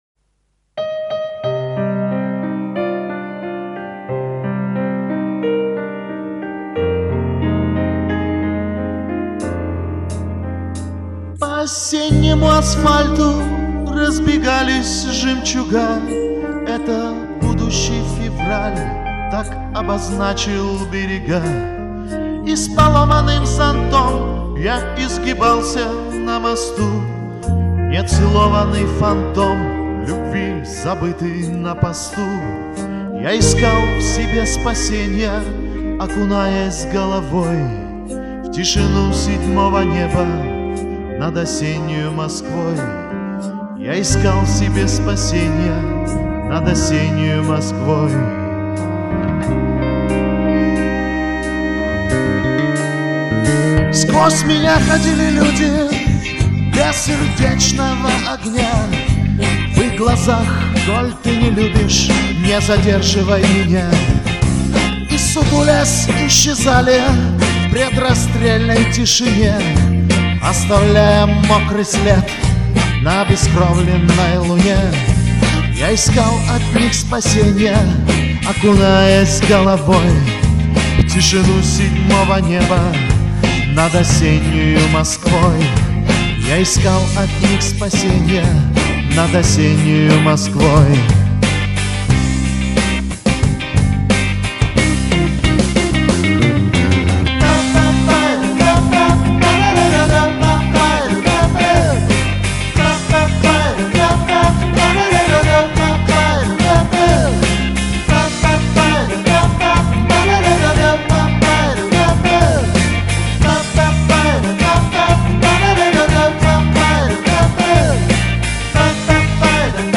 Хотел сказать - Спели душевно.